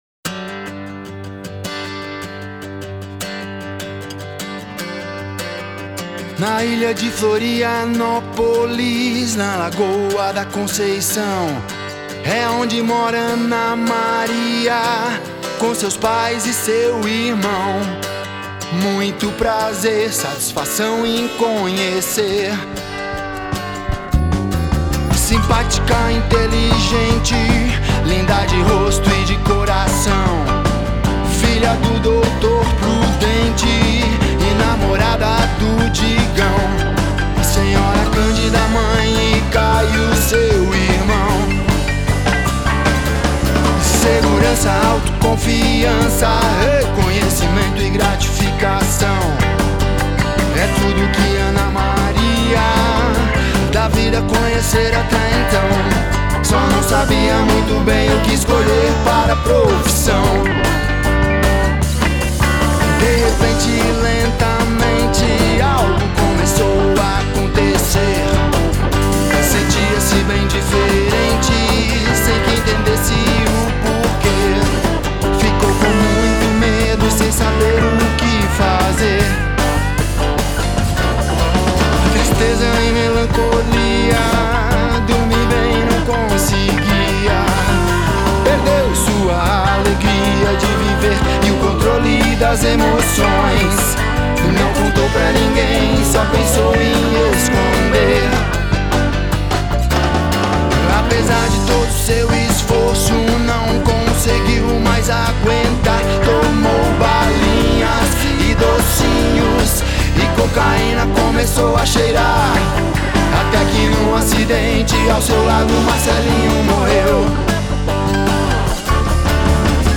tecladista